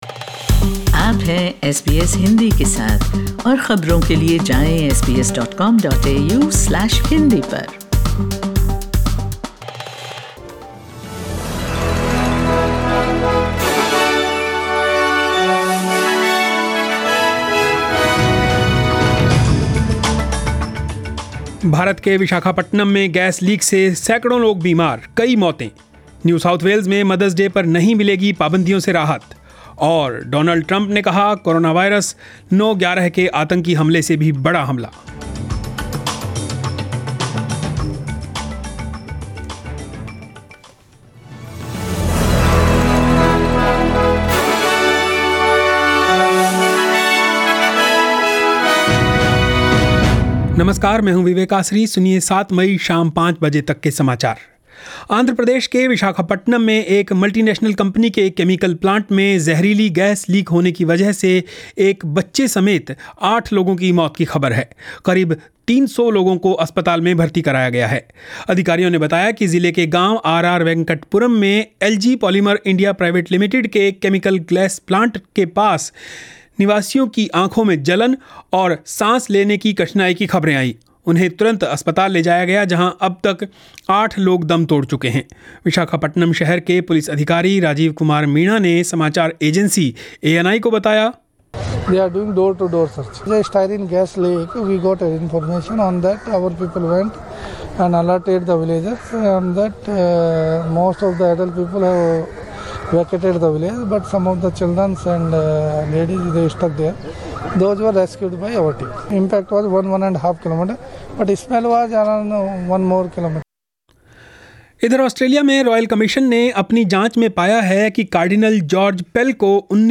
News in Hindi 07 May 2020